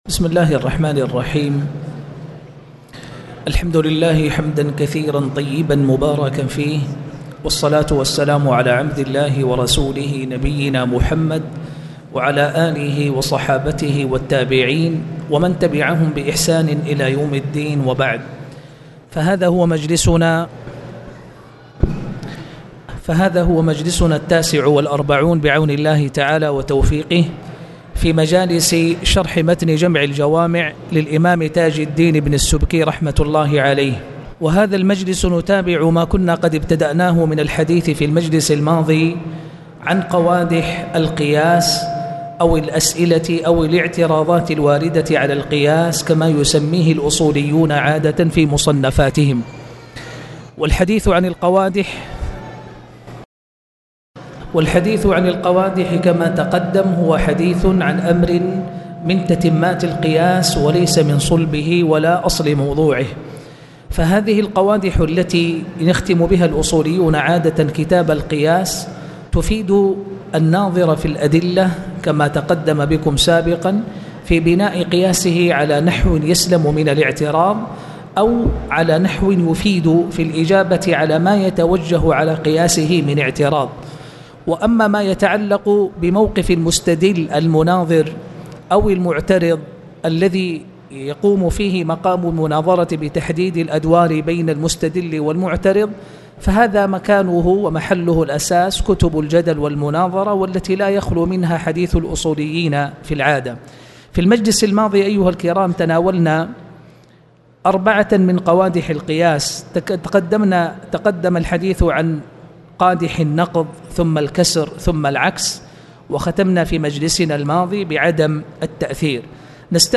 تاريخ النشر ٢٥ ربيع الثاني ١٤٣٨ هـ المكان: المسجد الحرام الشيخ